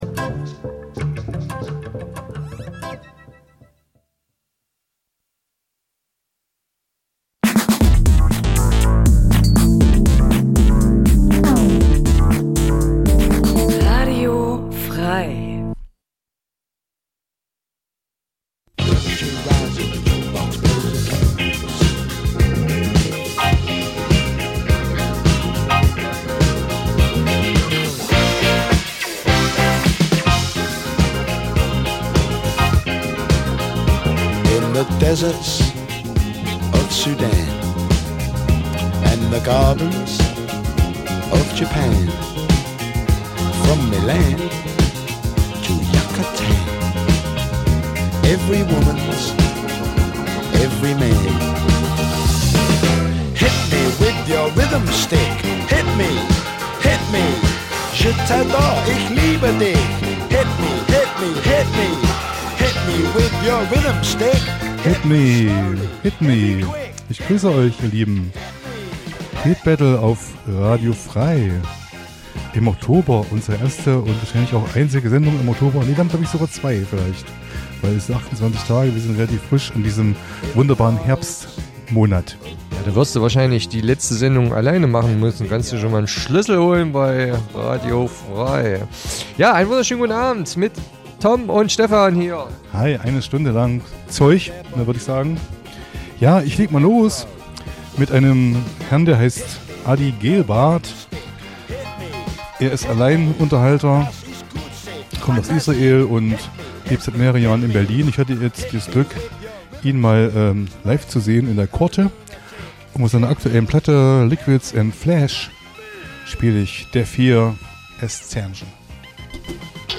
Zwei Musikfreunde duellieren sich mit raren Rock- und Punklegenden... - vinyl meets mp3... crossover zwischen den Welten, Urlaubsmusik und Undergroundperlen. Abgr�nde Ihres Musikgeschmacks tun sich scheinbar unkontrolliert auf.